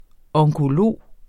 Udtale [ ʌŋkoˈloˀ ]